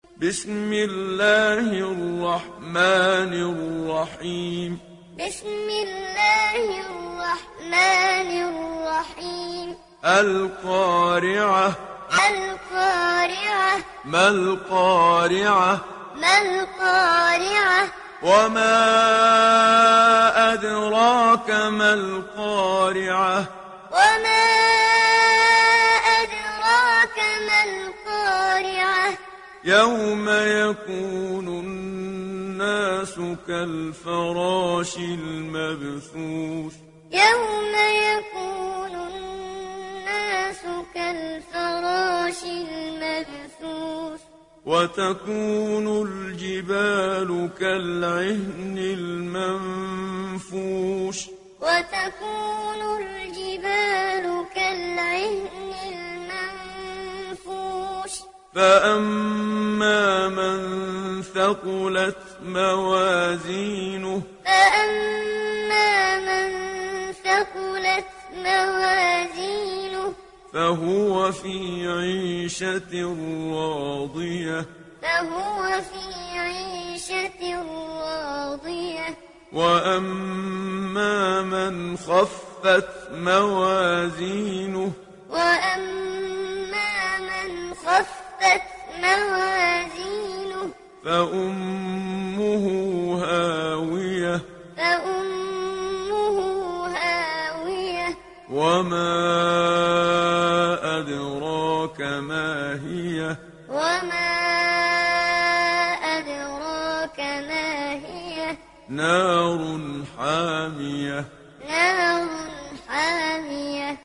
دانلود سوره القارعه محمد صديق المنشاوي معلم